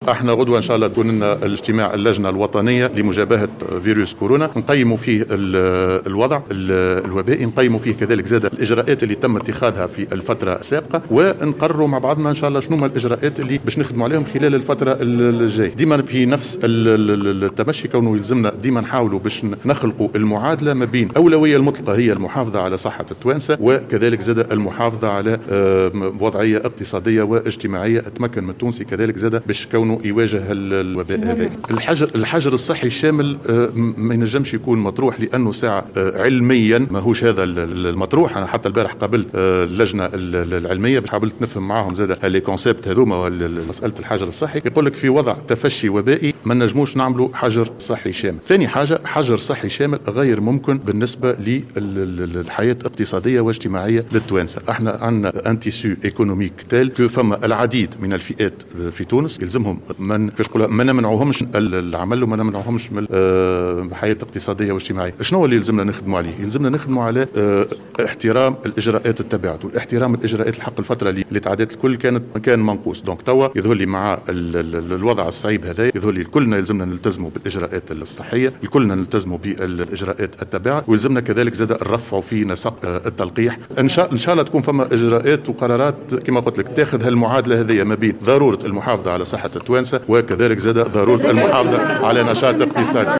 وأكد المشيشي، في تصريح للجوهرة أف أم، على هامش إشرافه اليوم على افتتاح مدرسة 'الفرصة الثانية' بباب الخضراء ، إن فرضية الحجر الصحي الشامل غير مطروحة نظرا لعدة أسباب علمية واجتماعية وإقتصادية.